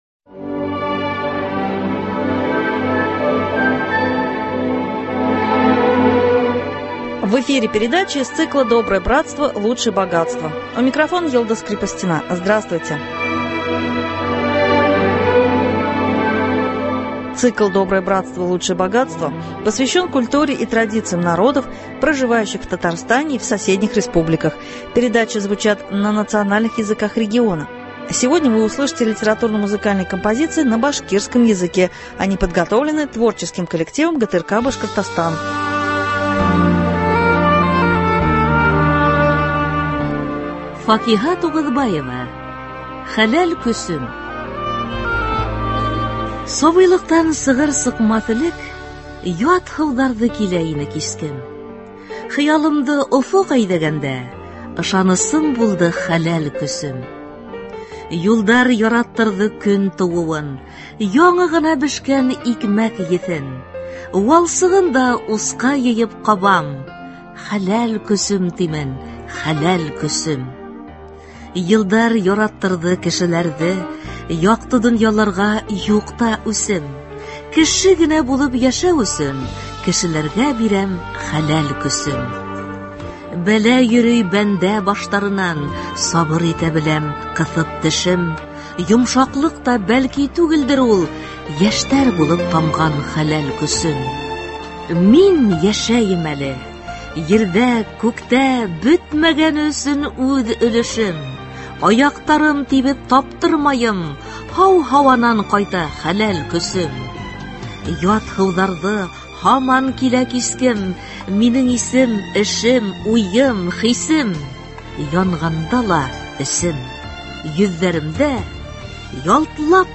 Литературно-музыкальная программа на башкирском языке.